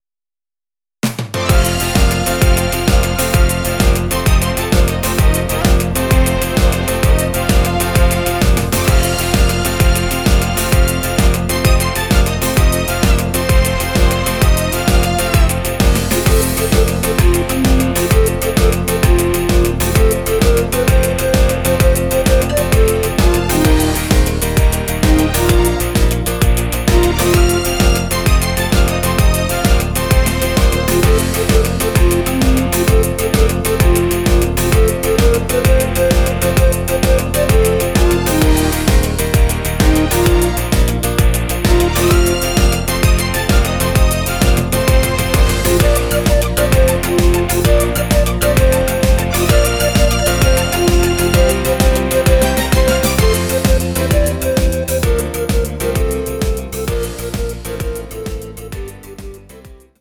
Audio Recordings based on Midi-files
German, 1980s